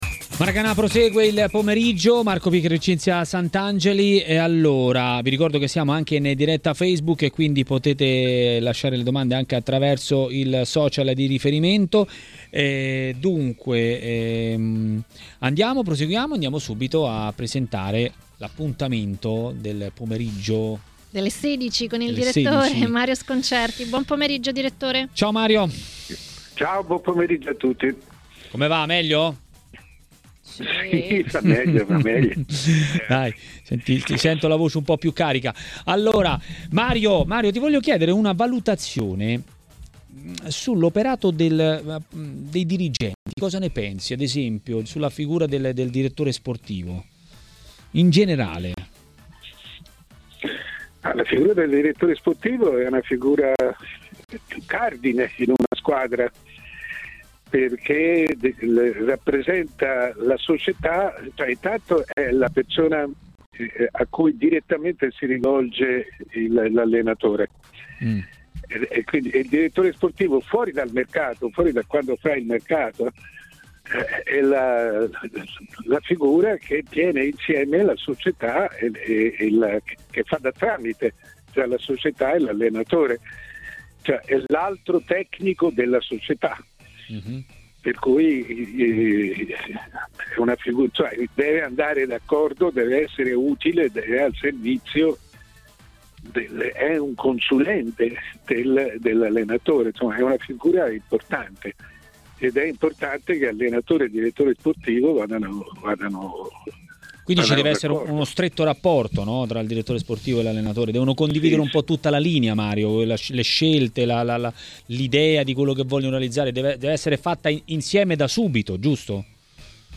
Dal caso Donnarumma a Pirlo: questi alcuni degli argomenti trattati a Maracanà, nel pomeriggio di TMW Radio, dal direttore Mario Sconcerti.